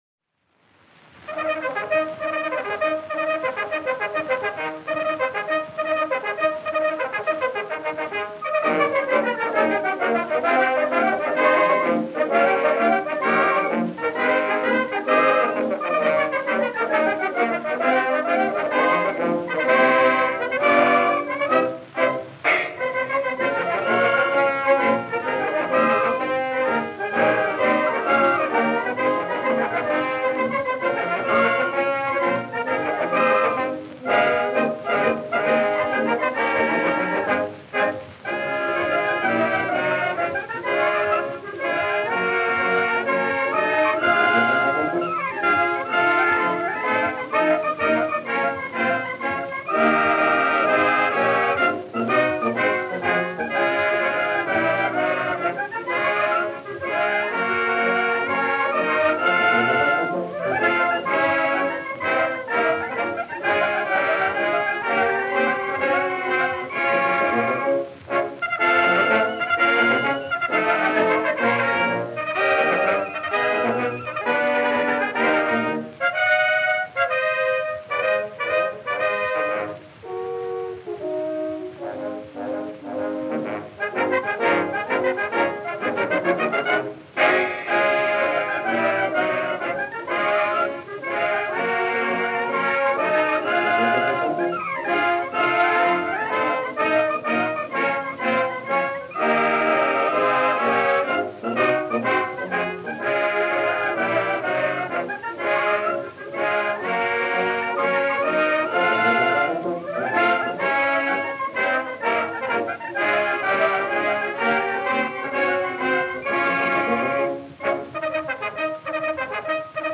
March